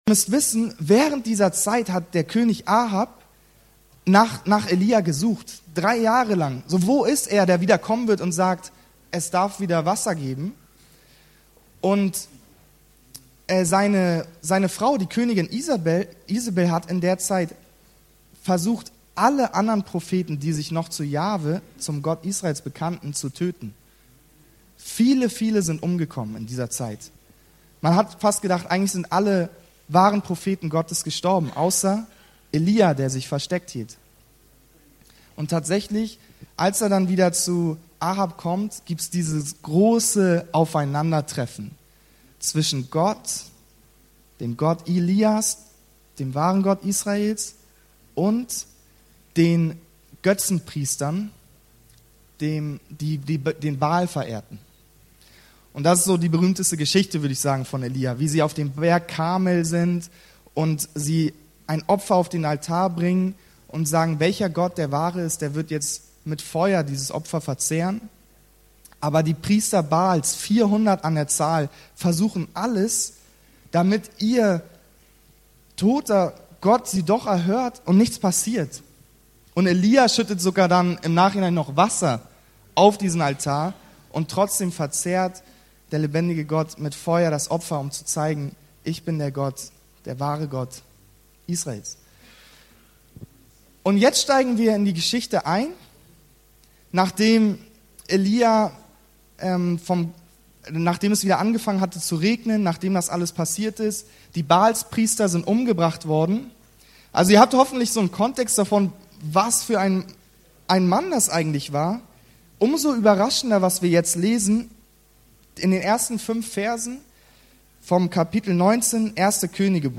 Durch einen technischen Fehler, fehlen leider die ersten 5 Minuten der Aufnahme.